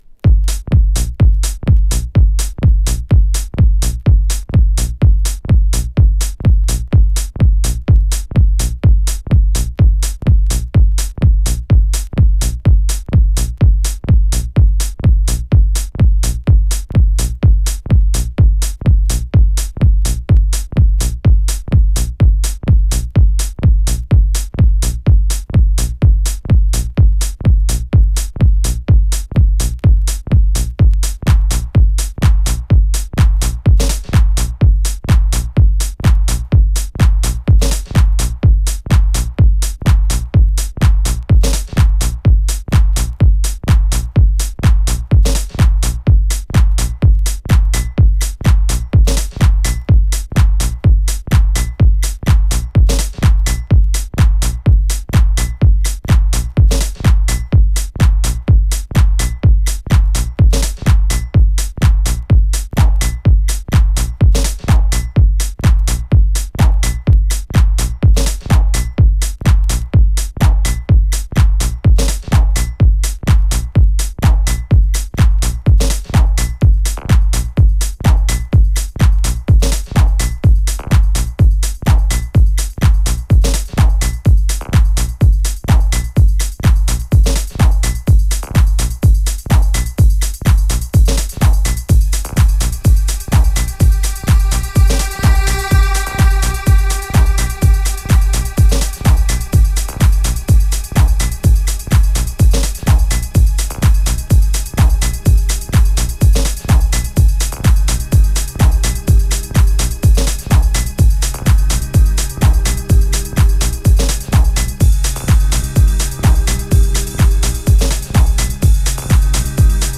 ファットな低音にバウンスするスネア、ダーティな味付けで展開するファンキーなエレクトリック・トラック2曲を収録。